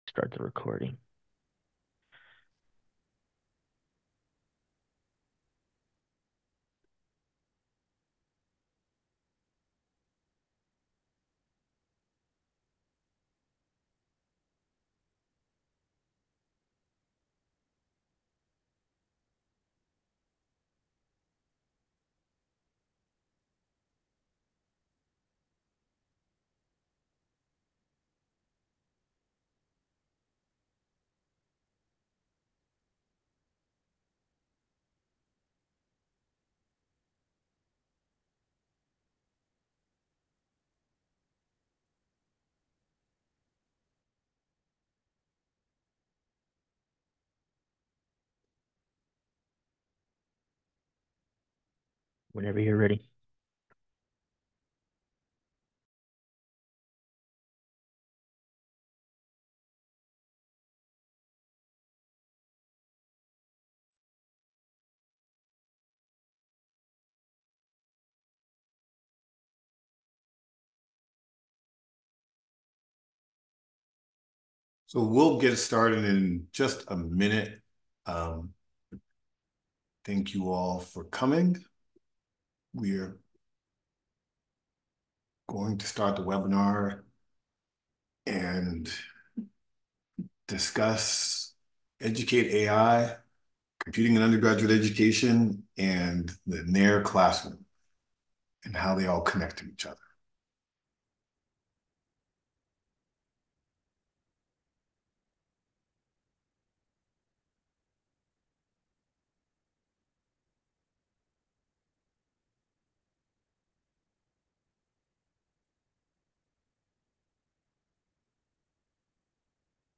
EducateAI and Computing in Undergraduate Education (IUSE:CUE) Webinar